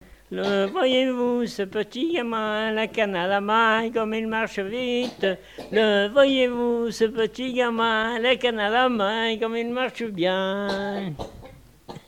Enfantines - rondes et jeux
danse : scottish
Pièce musicale inédite